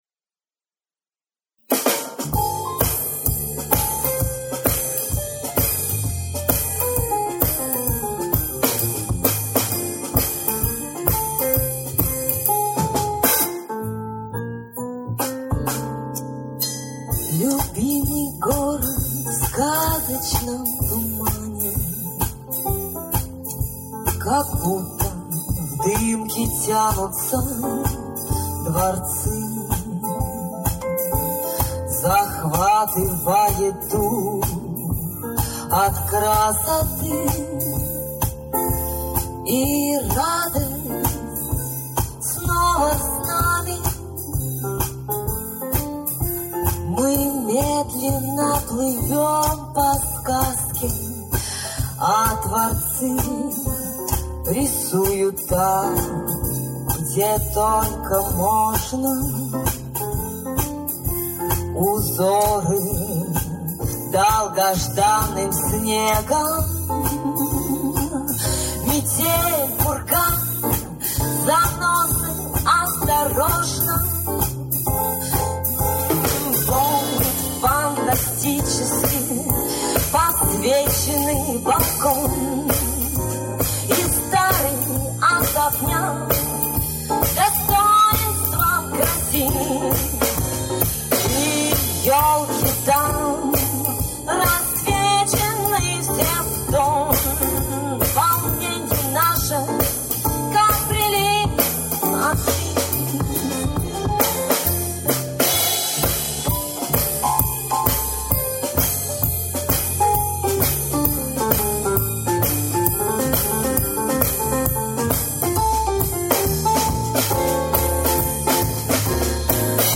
И вокалистка - поёт увереннее, понятно что не новичок в этом стиле.
Качество всего: и записи, и присутствие неизвестно то ли баса, то ли барабана, при всем этом и сам гулкий звук, не оставляют надежды на победу.